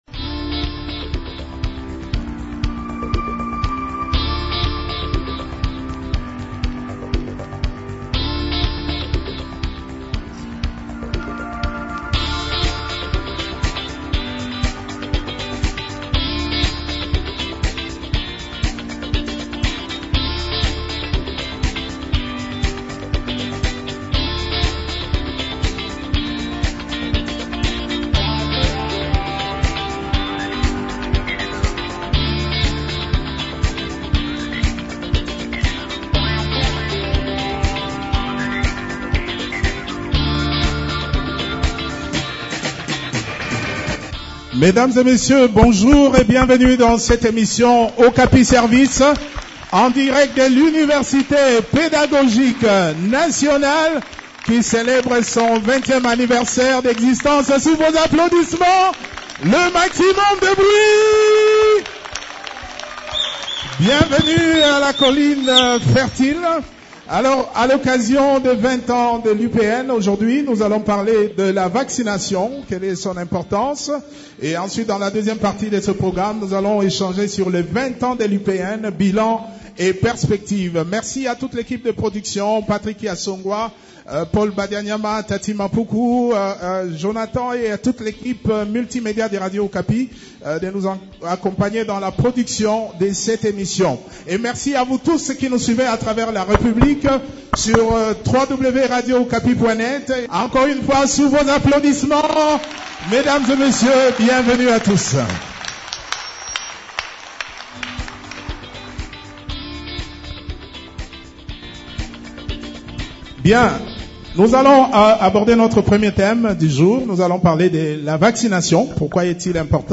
Dans une émission spéciale organisée à l’Université Pédagogique Nationale